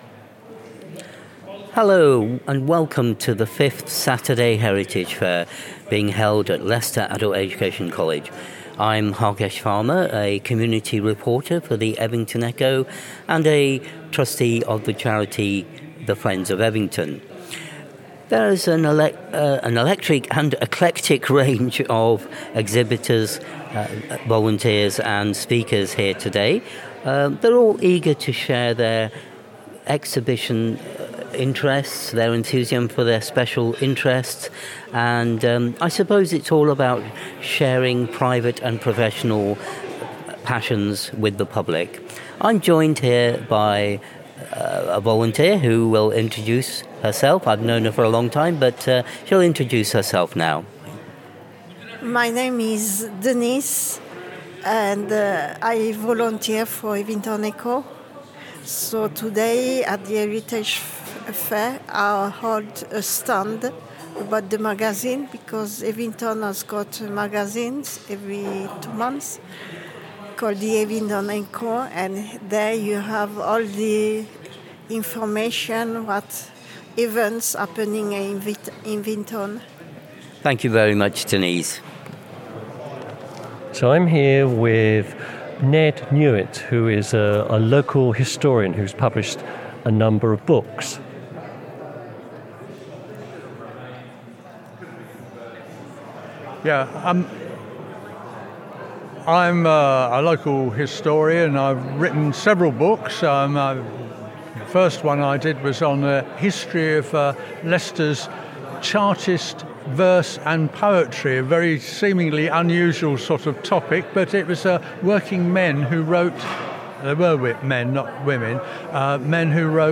The Saturday Heritage Fair at Leicester Adult Education College once again proved how rich and varied Leicester’s cultural story is. Recorded by Soar Sound Radio, this second set of podcast interviews captures a day filled with conversation, curiosity, and civic pride.